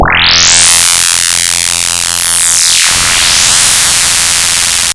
float wave = (440.0f + 220.0f*(sin(pi2*(count + i)/(float)samples))/4.0f) * pi2;
Ouch, my ears! ;)